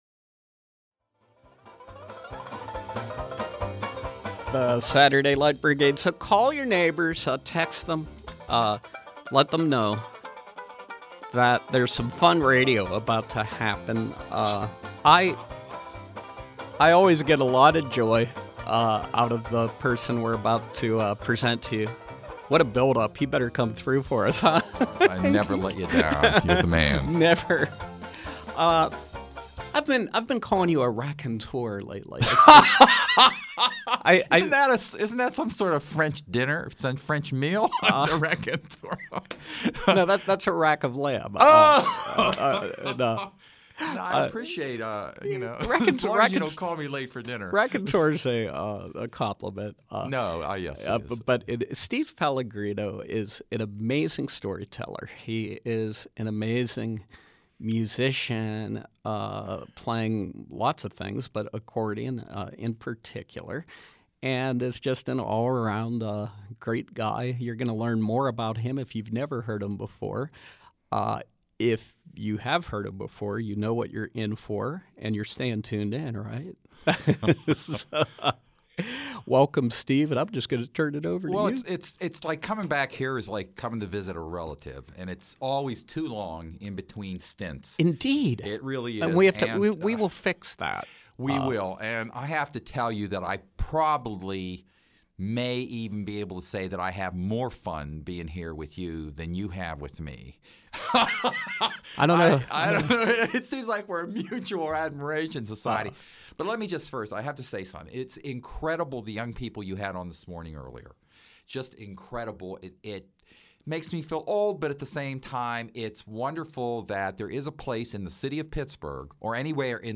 Live Music
Live music with accordionist and storyteller